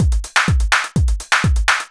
Juan Beat_125.wav